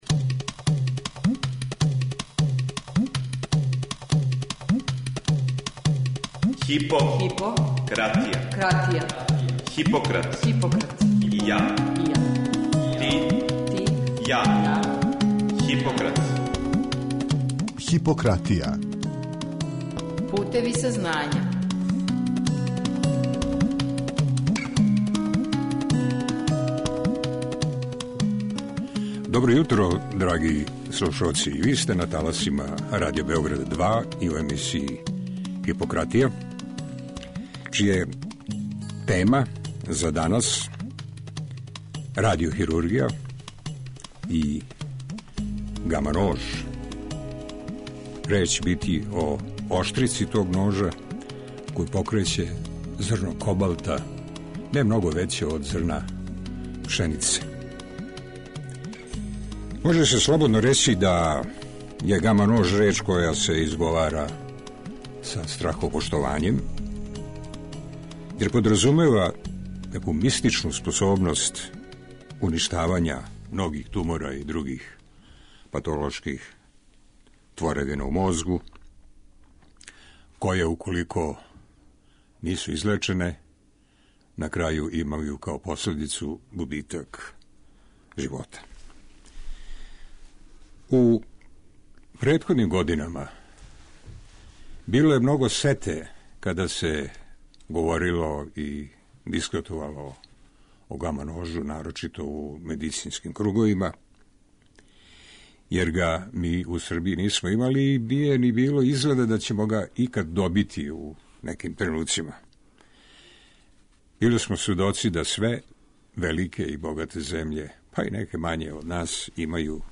Гошћа у студију је